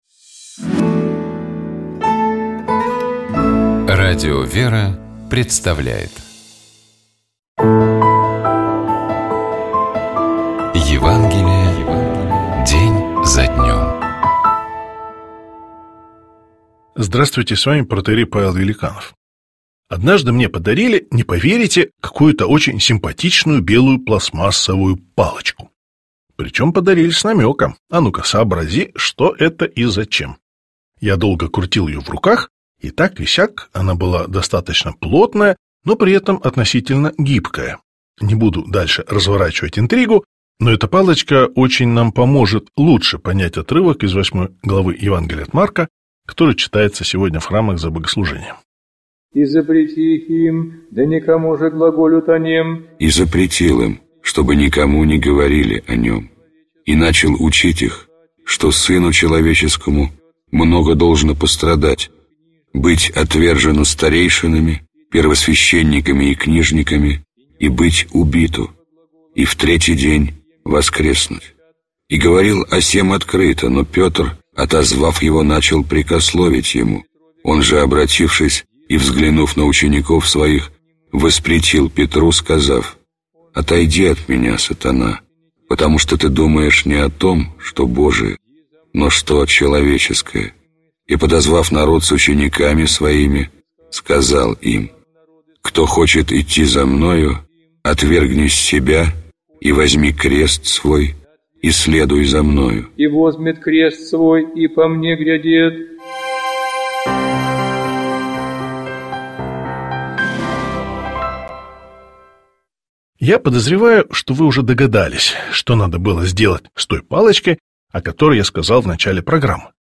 Читает и комментирует